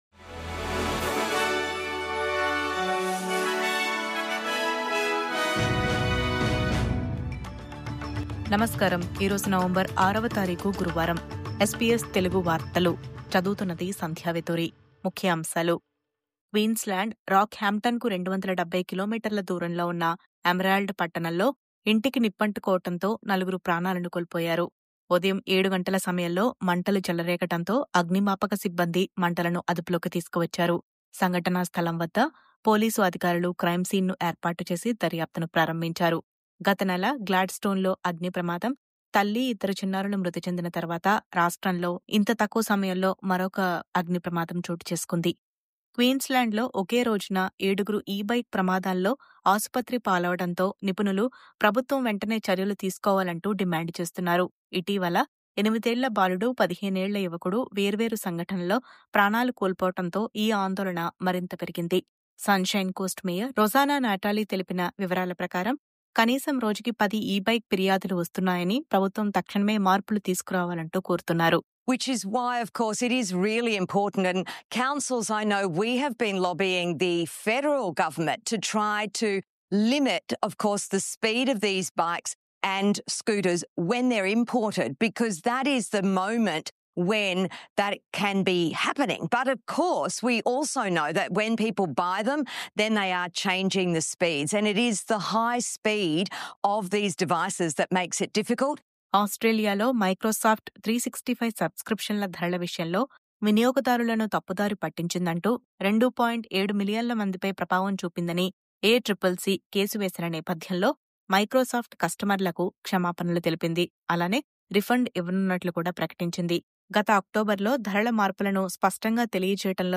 News update: ఒకే రోజు 7 e-scooter ప్రమాదాలు – ప్రభుత్వం వెంటనే చర్యలు తీసుకోవాలంటూ నిపుణుల డిమాండ్..